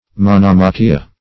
Search Result for " monomachia" : The Collaborative International Dictionary of English v.0.48: Monomachia \Mon`o*ma"chi*a\, Monomachy \Mo*nom"a*chy\, n. [L. monomachia, Gr.